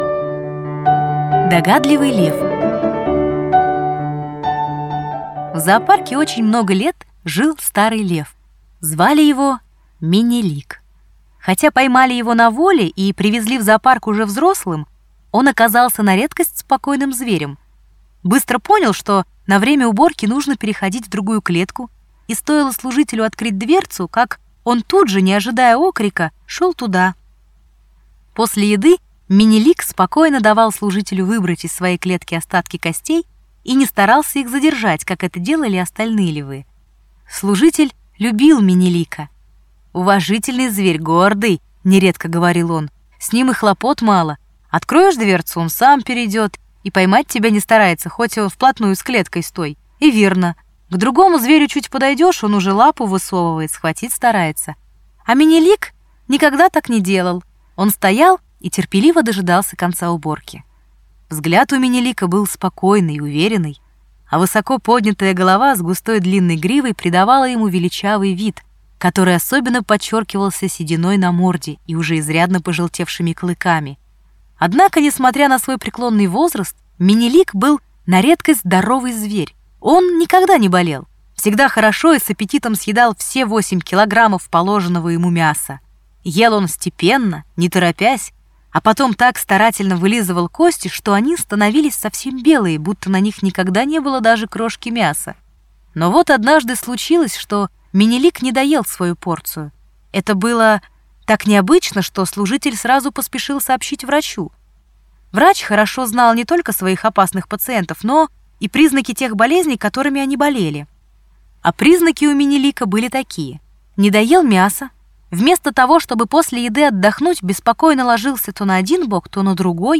Аудиорассказ «Догадливый лев»